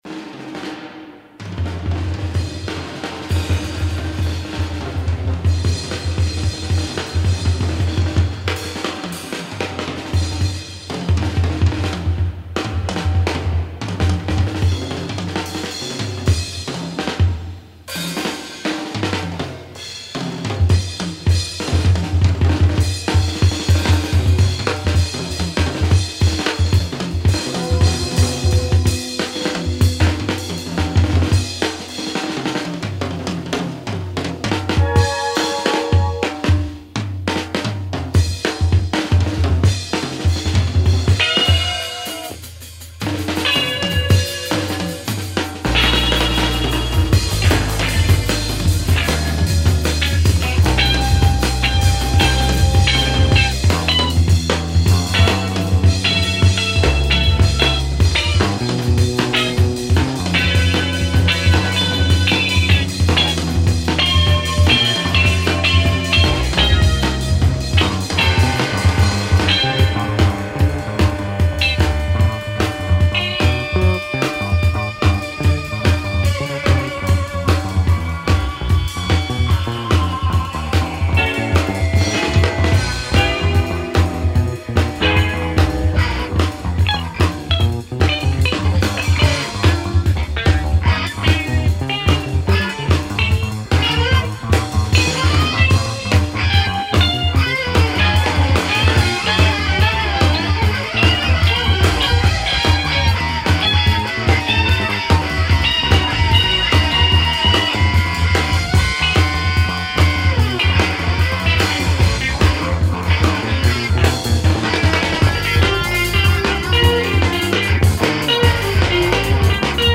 in Studio
core-jazz project
1992 scofield-guitar
noise-guitar
bass
drums